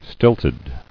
[stilt·ed]